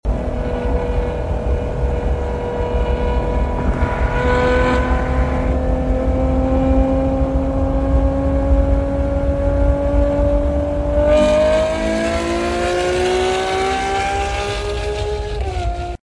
WITH a Mp3 Sound Effect The Ferrari 812. WITH a Screaaaaming V12 and Novitec exhaust.